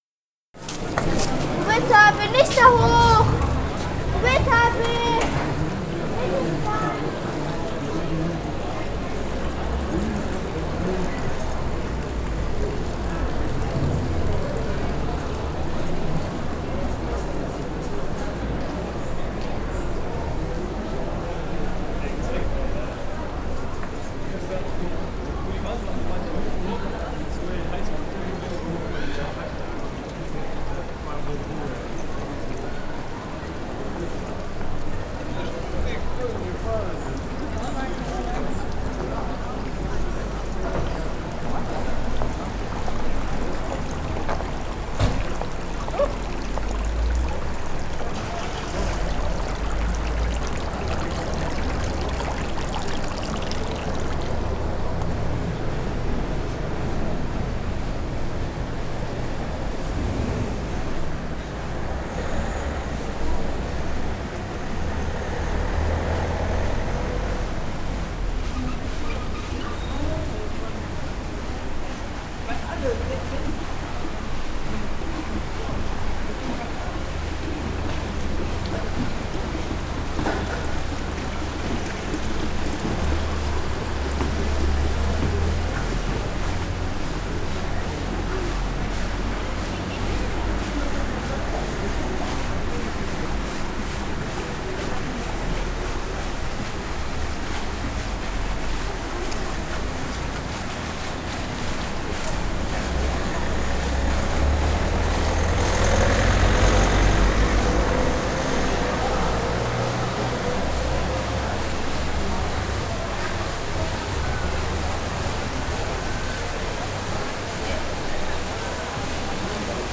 FELDSTUDIE GOEPPINGEN